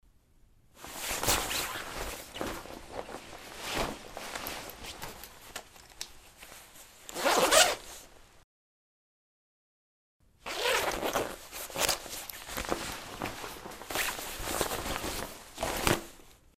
На этой странице собрана коллекция реалистичных звуков работы застёжки-молнии.
Молния застёжка на куртке